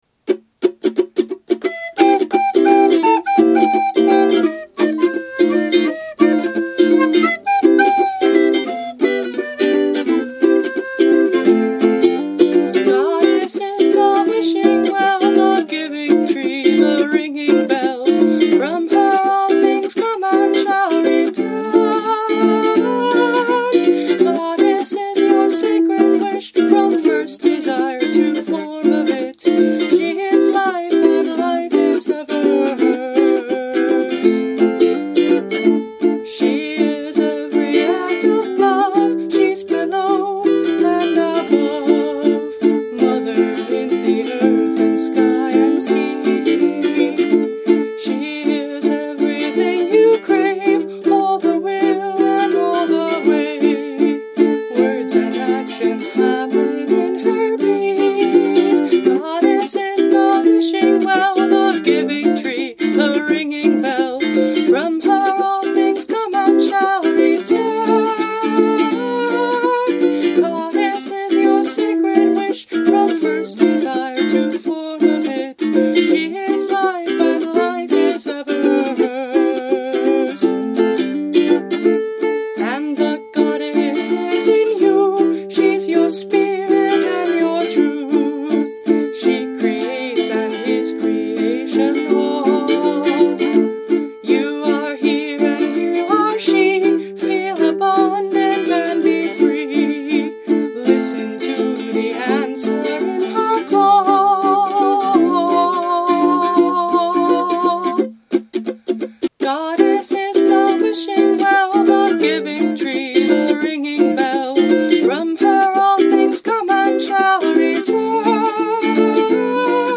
See related posts for more, and enjoy this upbeat song!
Soprano Recorder